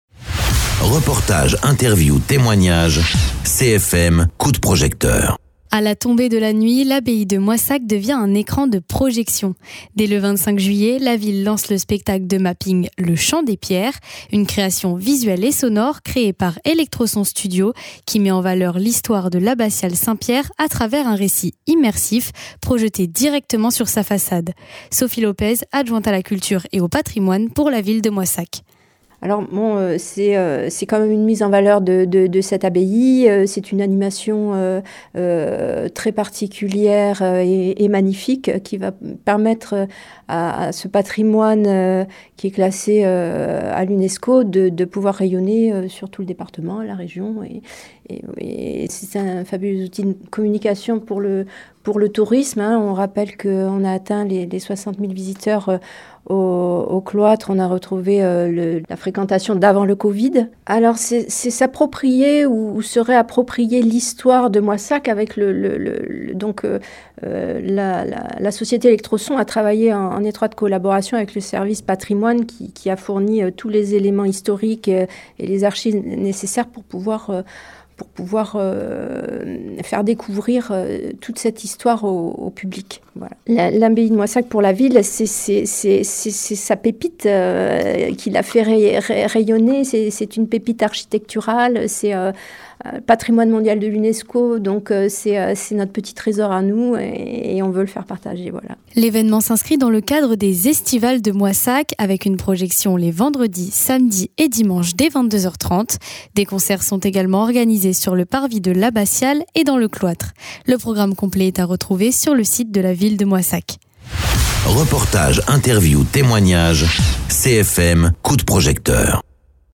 Interviews
Invité(s) : Sophie Lopez, adjointe à la culture et au patrimoine pour la ville de Moissac